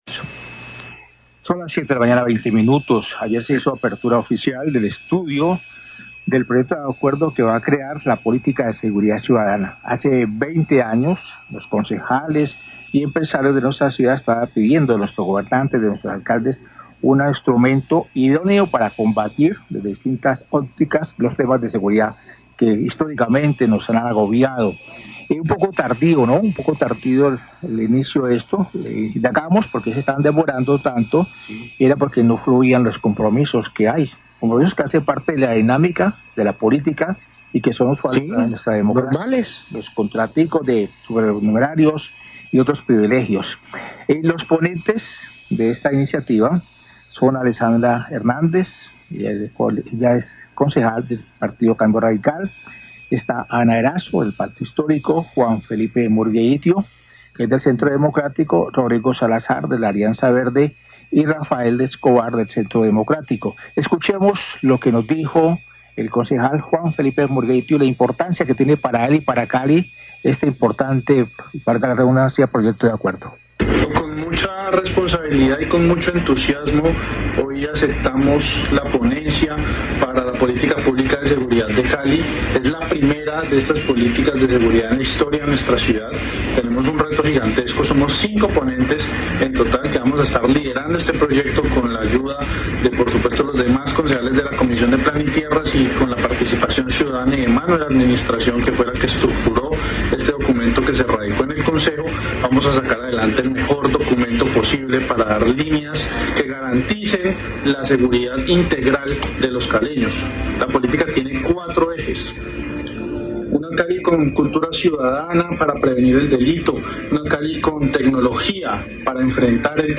Radio
Ayer se hizo apertura al estudio del proyecto de acuerdo que va a crear la política de seguridad ciudadana en el Concejo de Cali. Acerca del proyecto de acuerdo y de la importancia que tiene para la ciudad habló el concejal Juan Murgueitio.